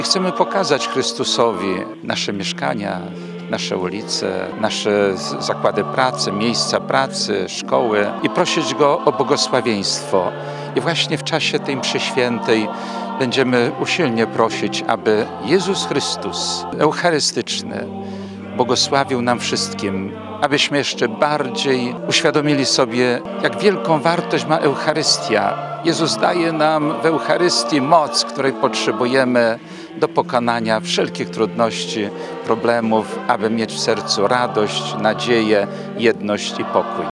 Poprzedziła ją msza święta na Placu Jana Pawła II, której przewodniczył ks. biskup Jerzy Mazur, ordynariusz Diecezji Ełckiej.
– Uroczystość Bożego Ciała to jedno z najważniejszych świąt – mówił ks. biskup Jerzy Mazur.